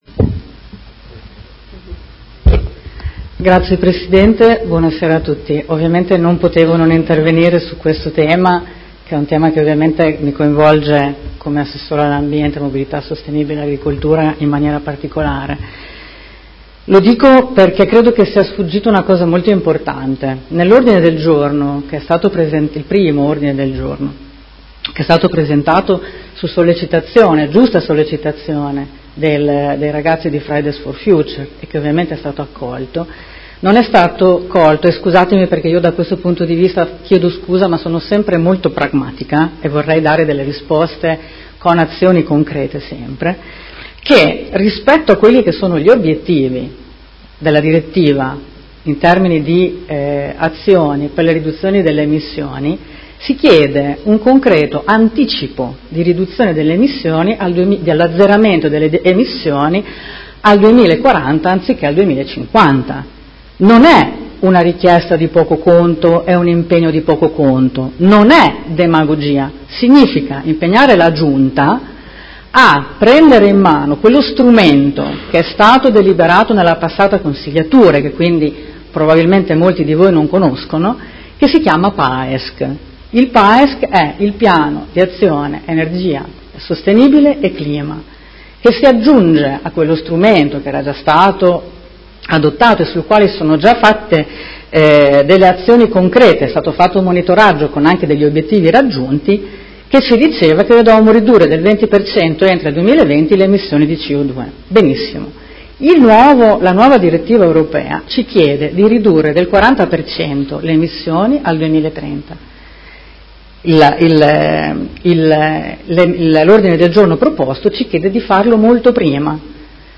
Seduta del 25/07/2019 Dibattito. Mozione nr. 187936 - Mozione nr. 221209 ed emendamenti